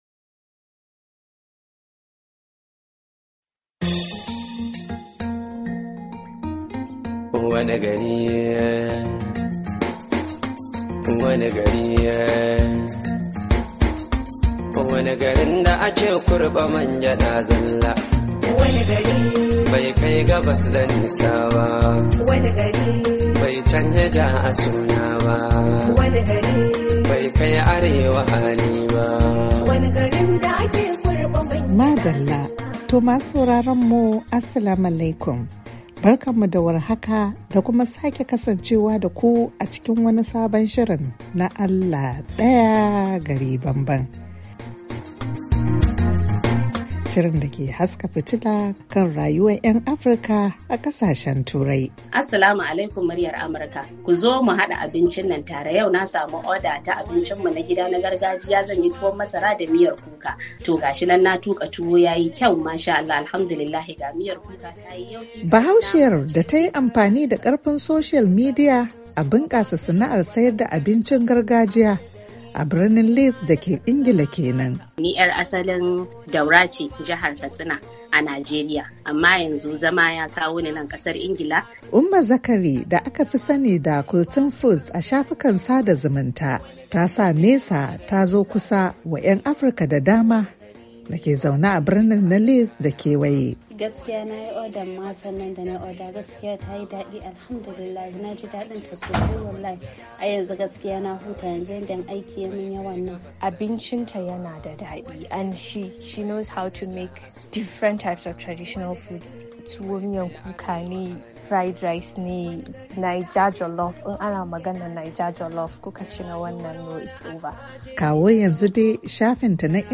A cikin shirin Allah Daya Gari Bambam na wannan makon mun zanta da Bahaushiyar da ake ribibin abincinta na gargajiya a Ingila